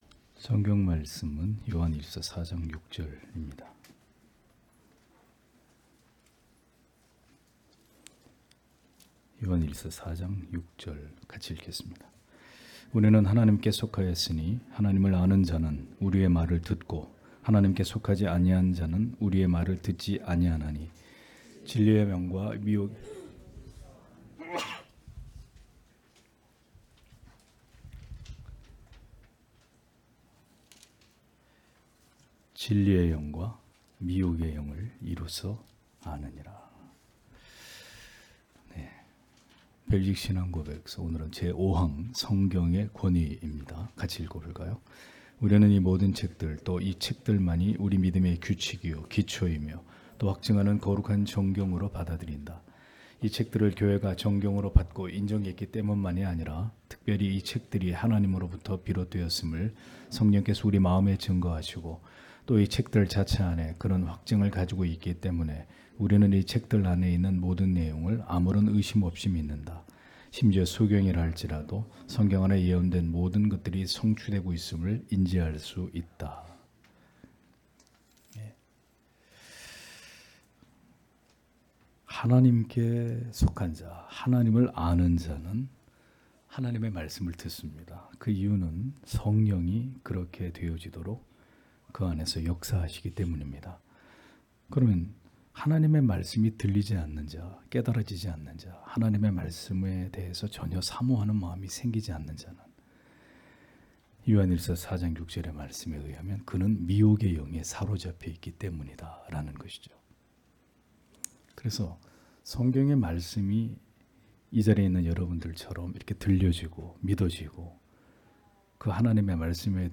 주일오후예배 - [벨직 신앙고백서 해설 5] 제5항 성경의 권위 (요일 4:6)